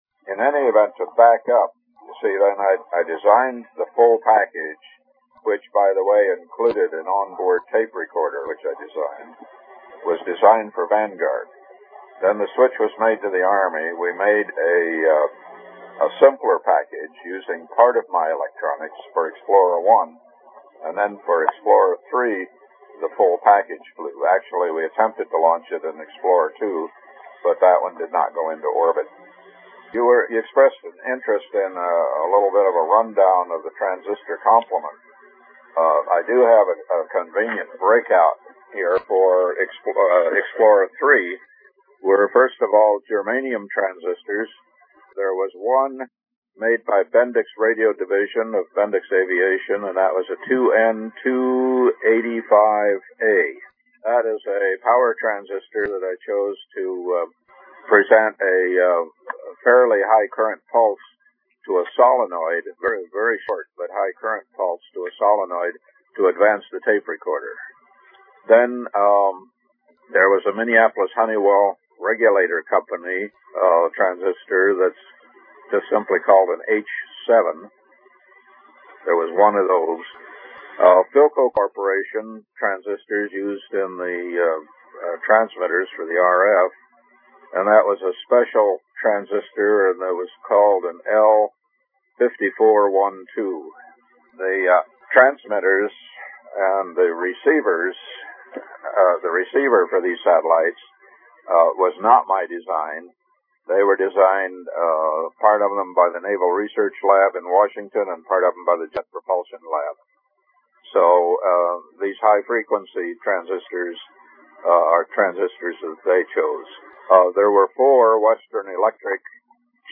A Transistor Museum Interview